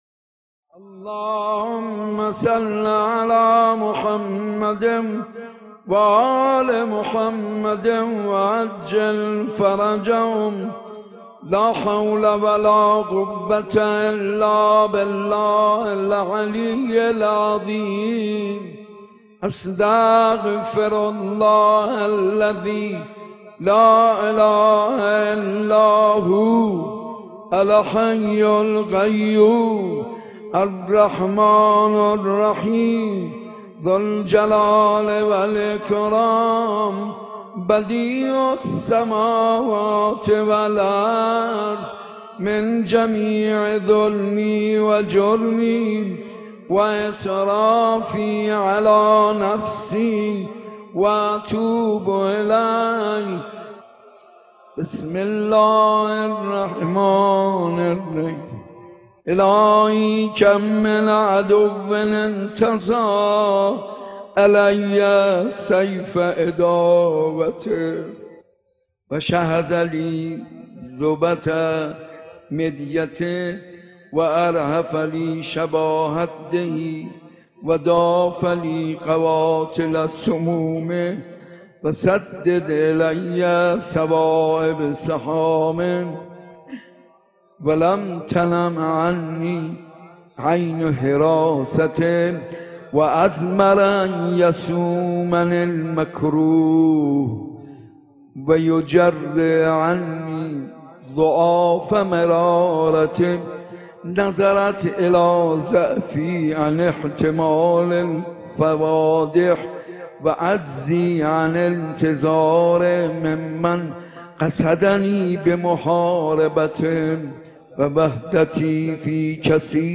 مسجد ارک
گلچین مراسم شب بیست و پنجم